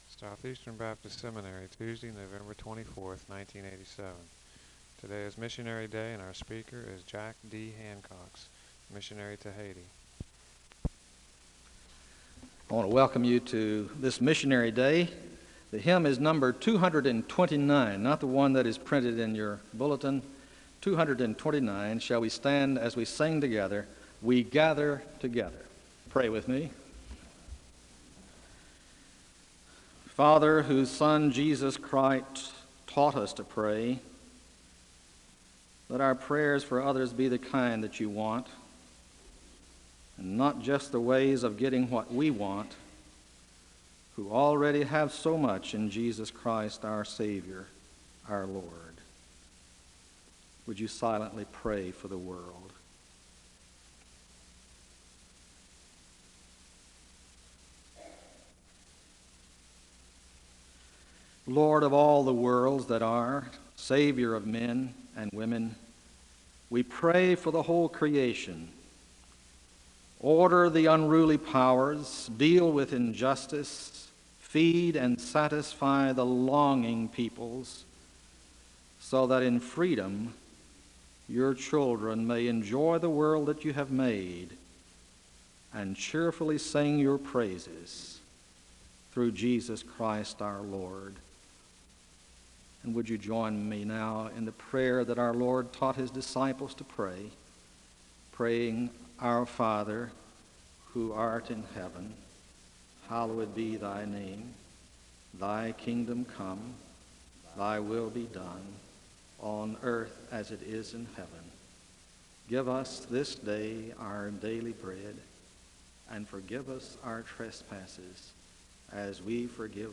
The service begins with a welcome and a moment of prayer (0:00-2:19). The congregation joins in singing the anthem (2:20-7:16).
There is a moment of prayer (33:16-34:23).